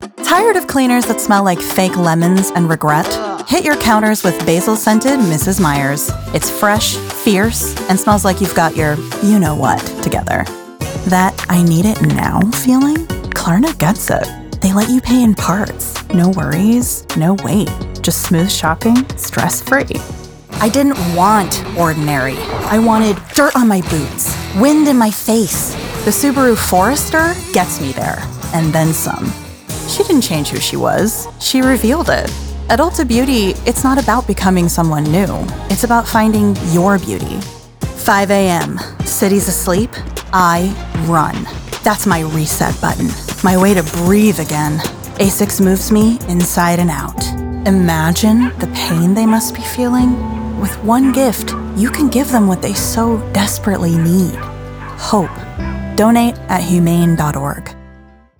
Commercial Demo
It’s smooth at the core, with just a hint of natural sparkle that keeps the sound lively, modern, and expressive.